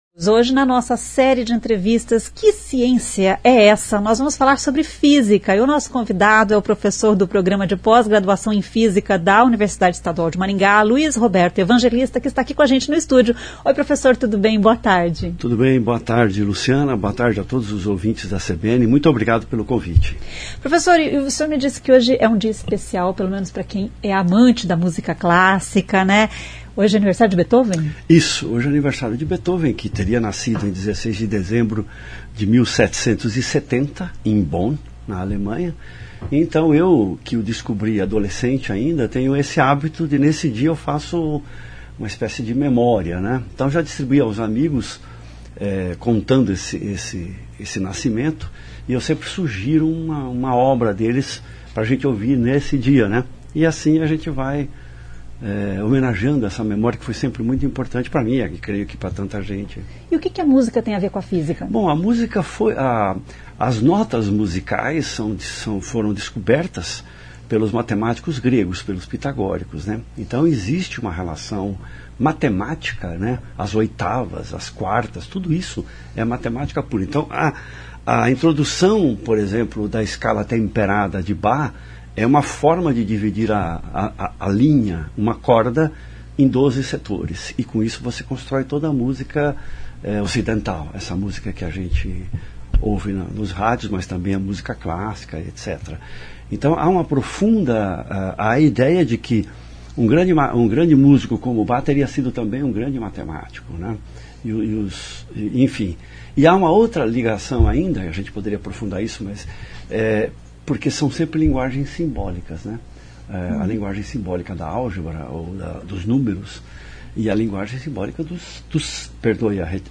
Nessa série de entrevistas o professor do Programa de Pós-Graduação em Física da UEM fala sobre a Física.